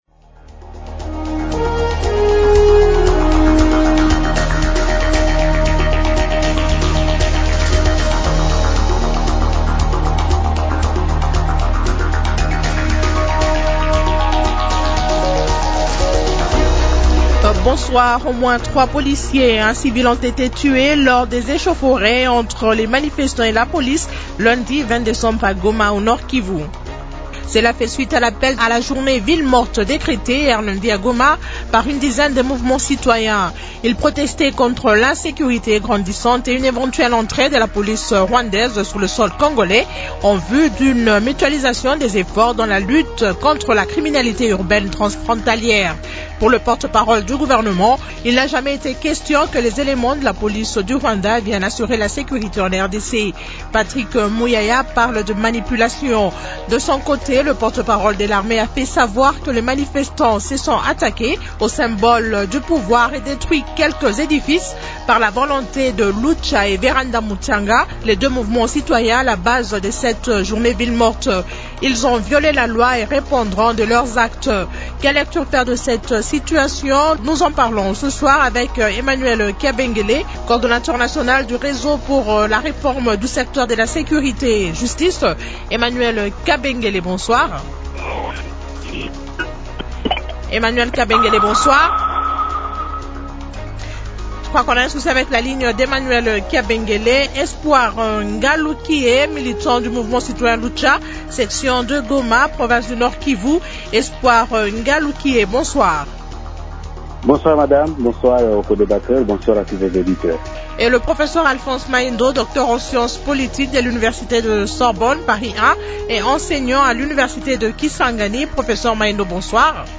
Quelle lecture faire de cette situation ? Invités :